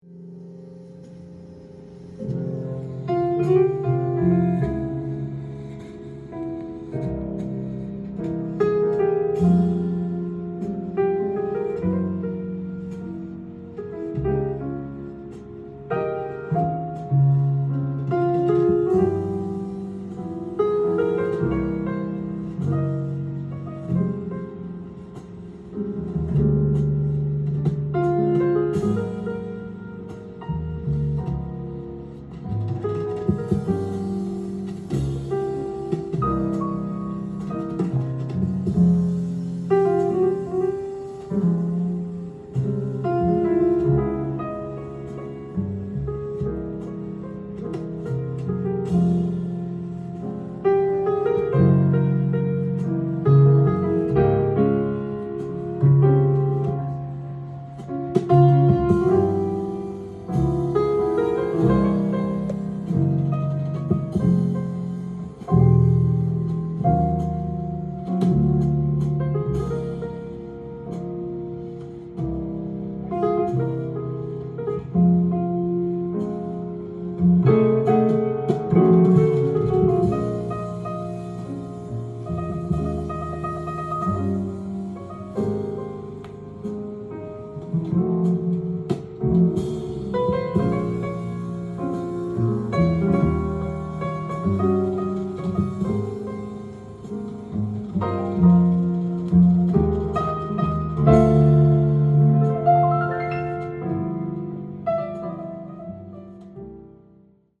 ジャンル：JAZZ-ALL
店頭で録音した音源の為、多少の外部音や音質の悪さはございますが、サンプルとしてご視聴ください。